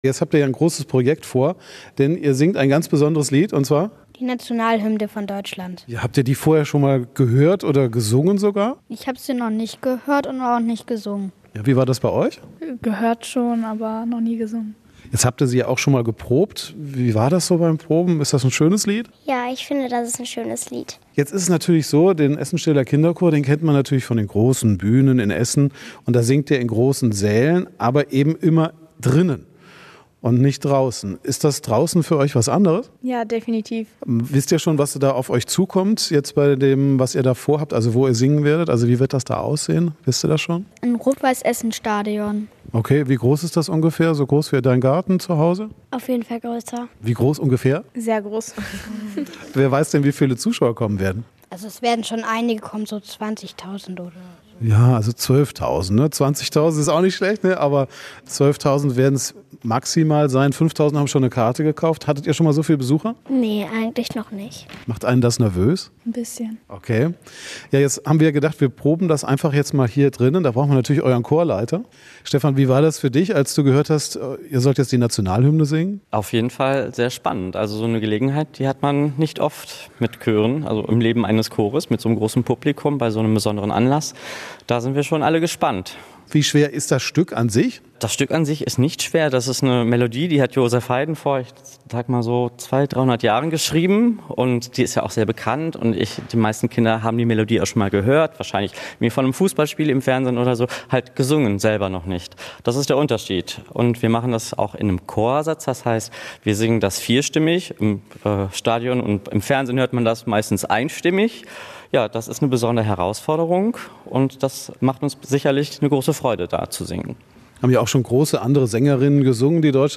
Dem Kinderchor aus Steele beschert das einen besonderen Auftritt. Radio Essen war bei den Proben dabei.